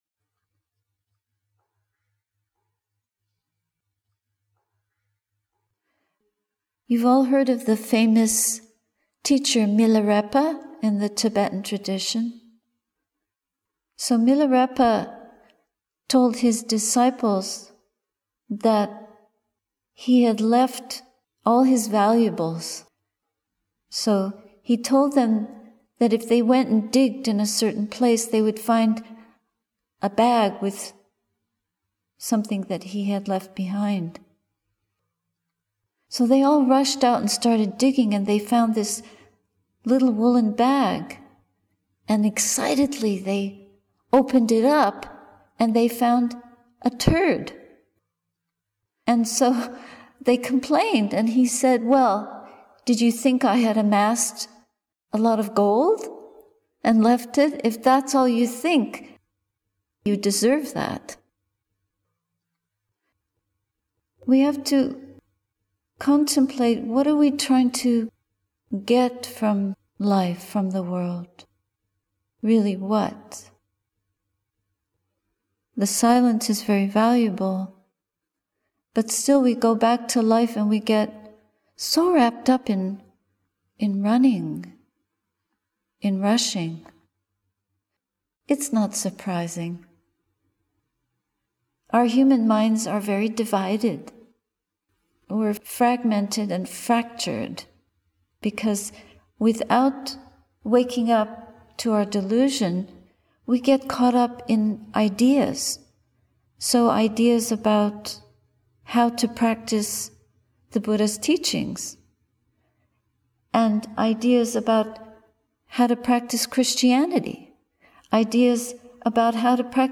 Satipaññā Insight Meditation Retreat, Chapin Mill, Batavia, NY, USA, Aug. 28. 2021 https